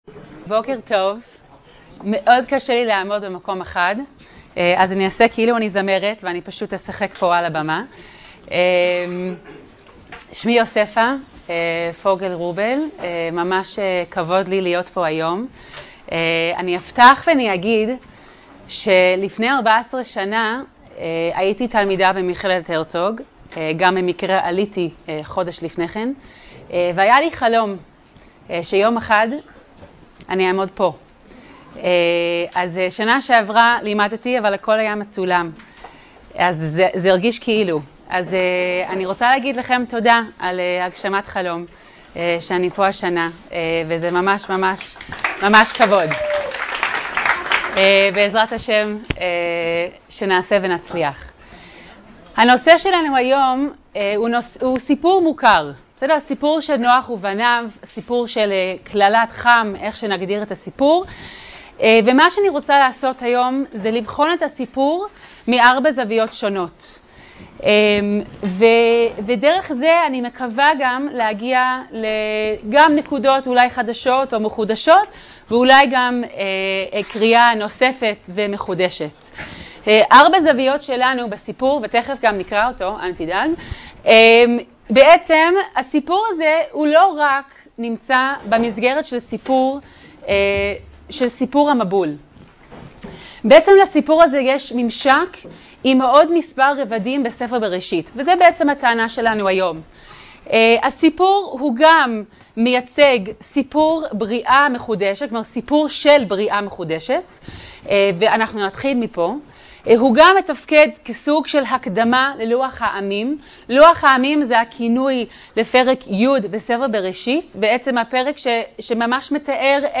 השיעור באדיבות אתר התנ"ך וניתן במסגרת ימי העיון בתנ"ך של המכללה האקדמית הרצוג תשפ"ב